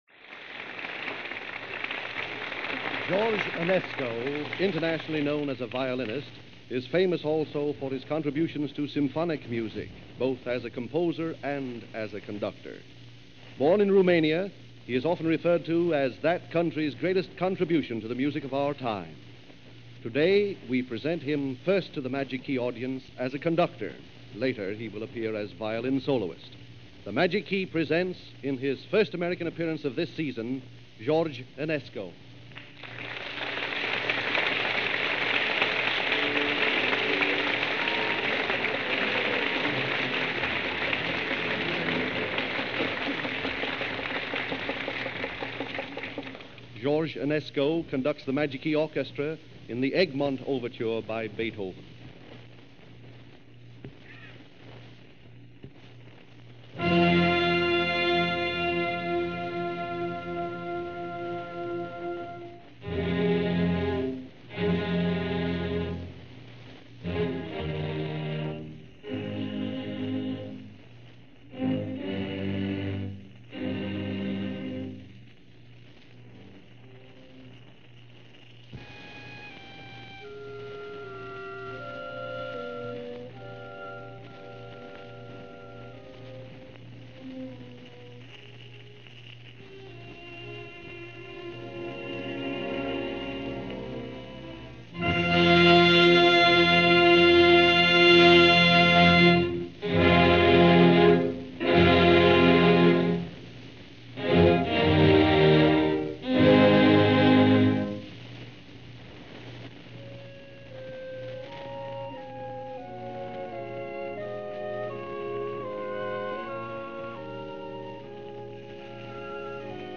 Georges Enescu Plays Music Of Beethoven And Mendelssohn In Concert - 1937 - Past Daily Weekend Gramophone
Georges Enescu Plays Music Of Beethoven And Mendelssohn in concert - 1937 - Broadcast December 26, 1937 - RCA Magic Key Program - Past Daily.
Georges-Enesco-in-Concert-1937.mp3